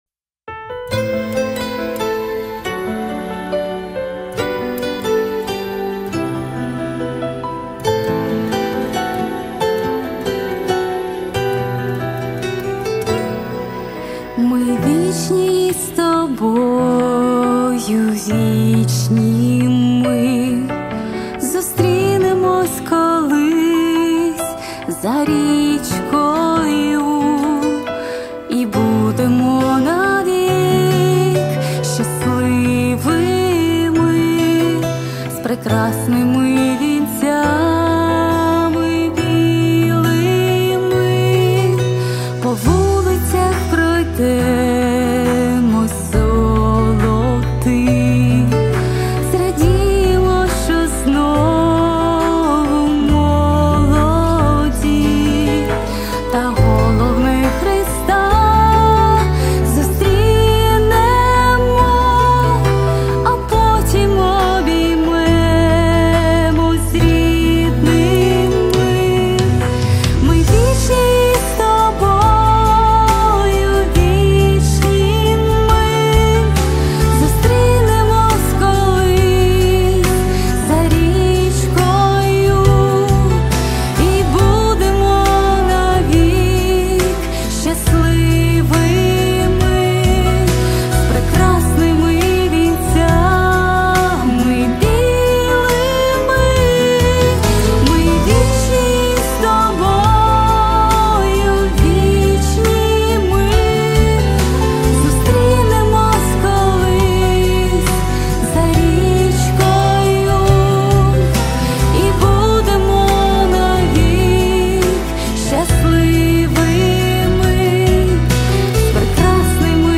169 просмотров 174 прослушивания 19 скачиваний BPM: 138